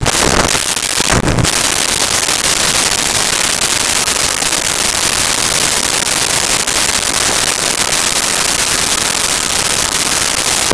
Radio Static Old
Radio Static Old.wav